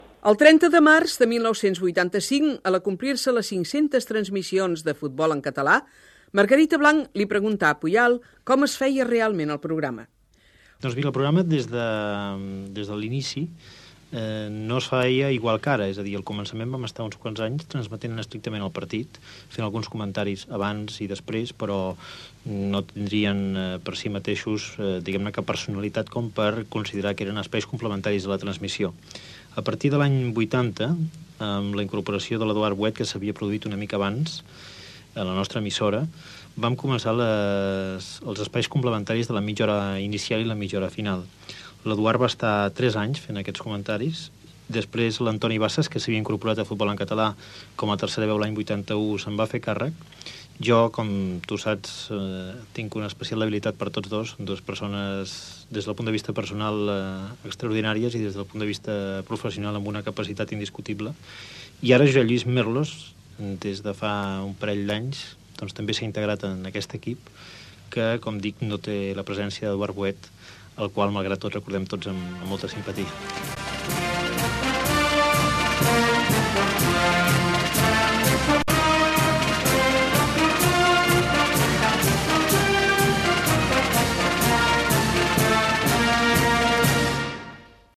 Joaquím Maria Puyal parla de l'evolució de les transmissions del Futbol en català a Ràdio Barcelona des del seu inici fins el 1985.
Divulgació